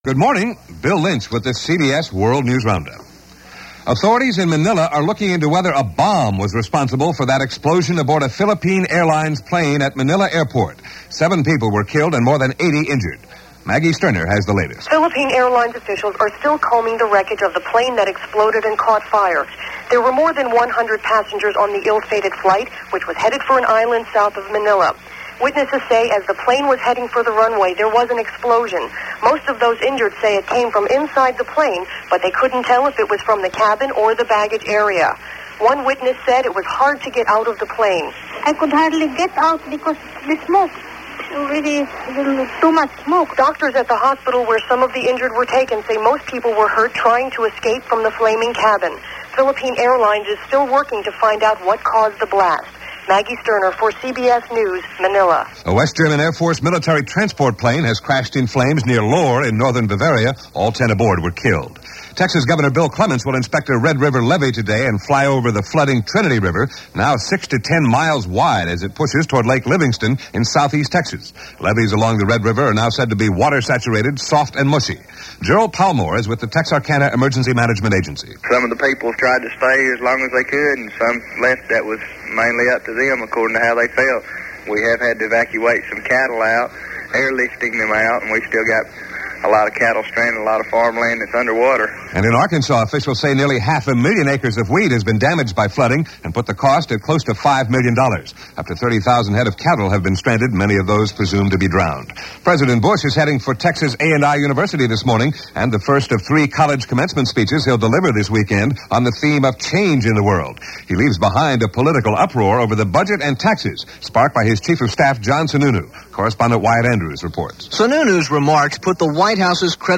And that’s a small sample of what went on, this May 11, 1990 as reported by The CBS World News Roundup.